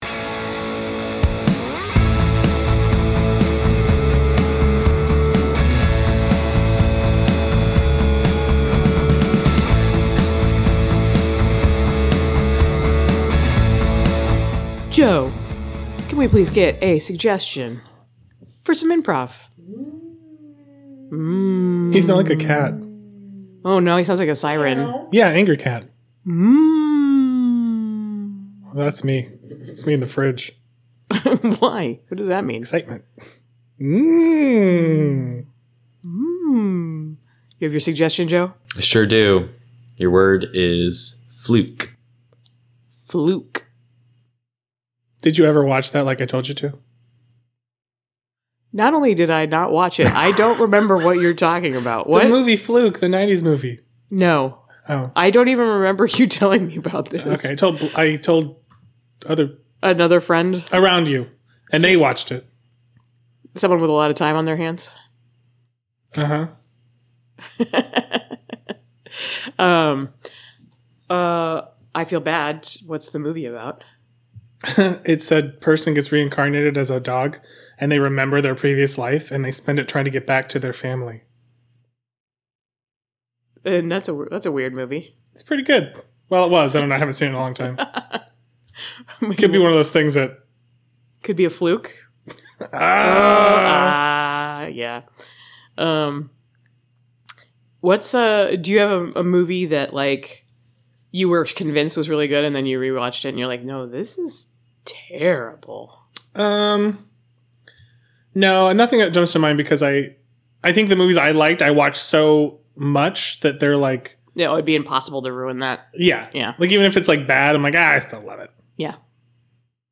Bonus IMPROV - I Use Scissors To Open My Capri Sun